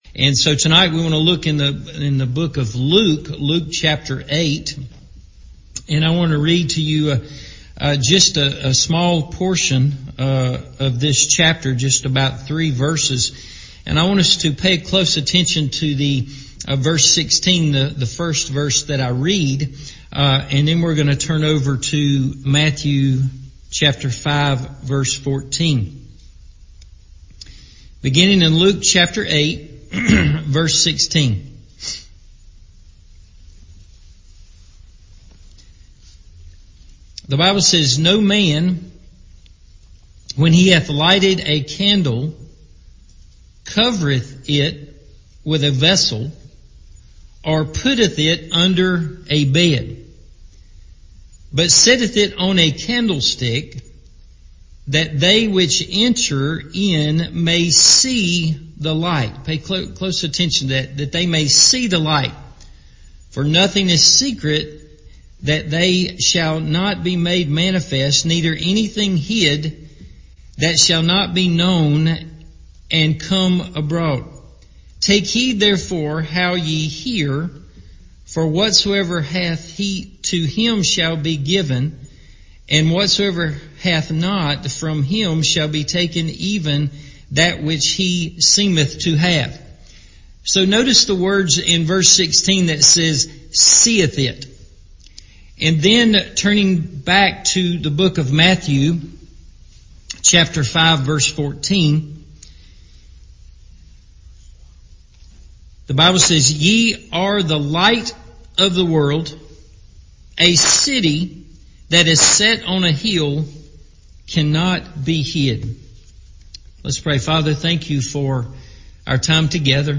The Value of Light – Evening Service